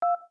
Index of /phonetones/unzipped/LG/GS390-Prime/DialPad sounds/Beep
DialPad2.wav